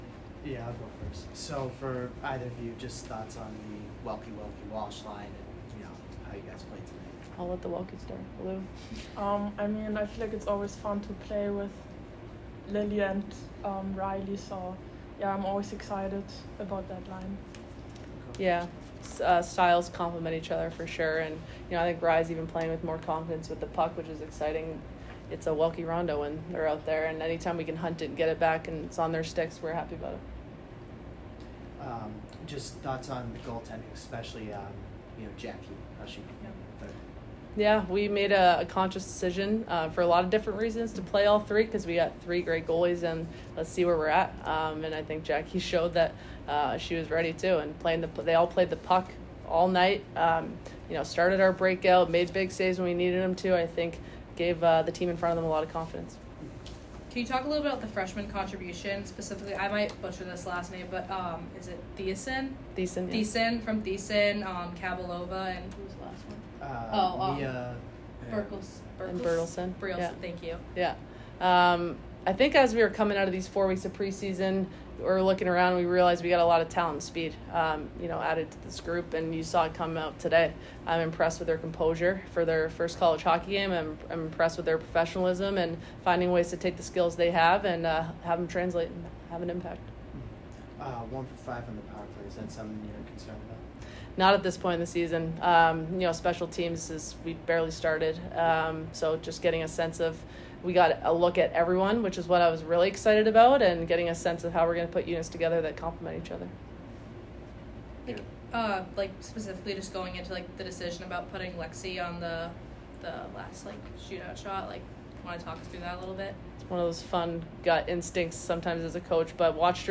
Women's Ice Hockey / Concordia Postgame Interview